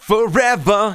Index of /90_sSampleCDs/Techno_Trance_Essentials/VOCALS/SUNG/C#-BAM